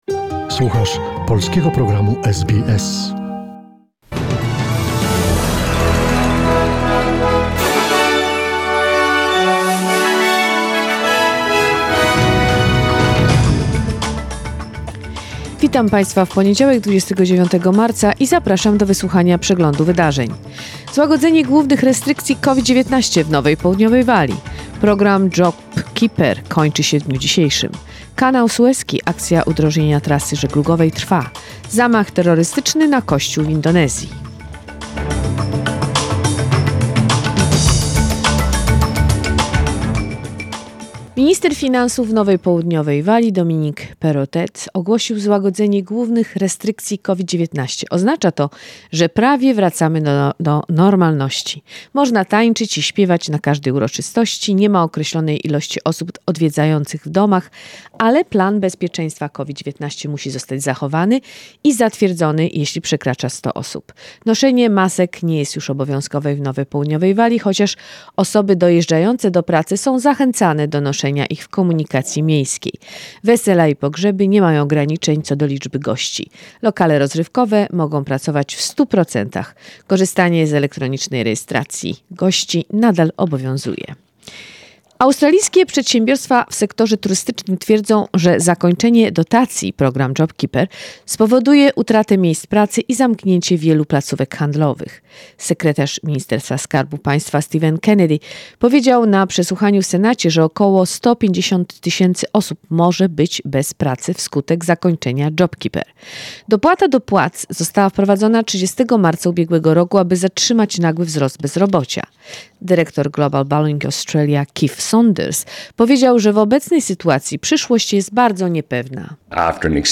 Wiadomości SBS, 29 marca 2021 r.